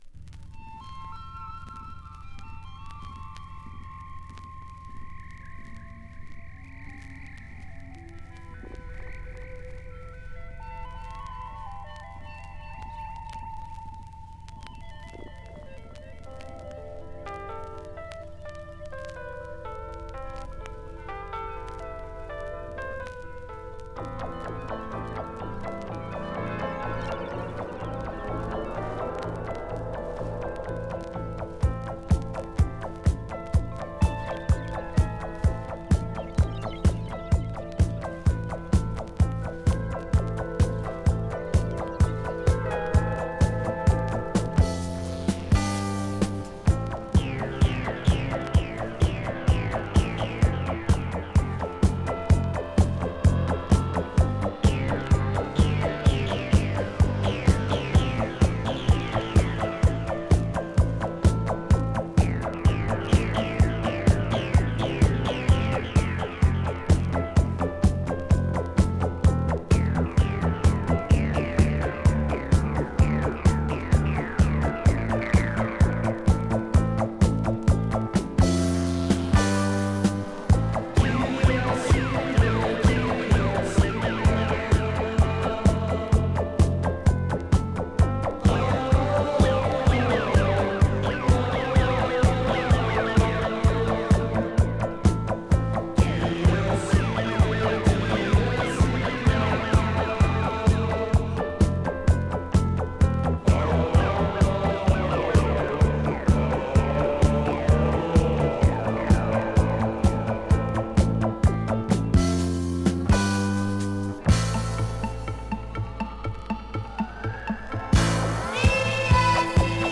French Cosmic Disco!
スペイシーなシンセサイザーが印象的な哀愁系コズミック・ディスコ！